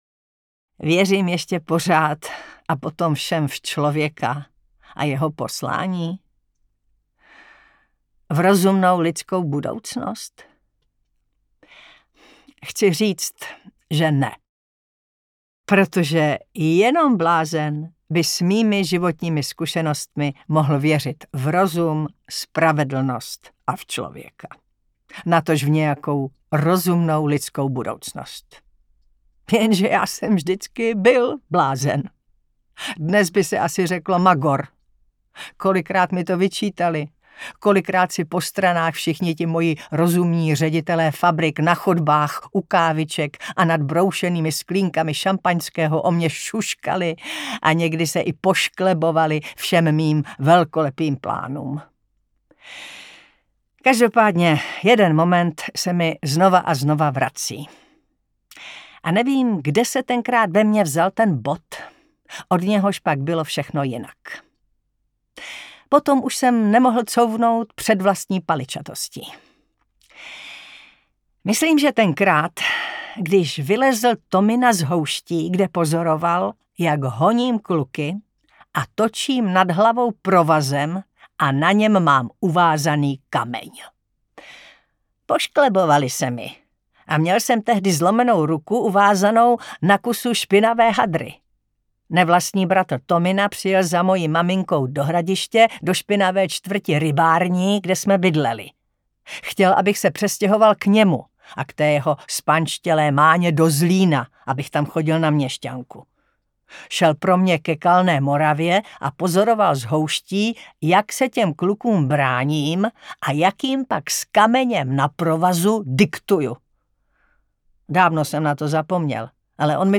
Audiobook
Read: Taťjana Medvecká